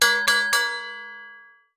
Boxing Bell Fight End.wav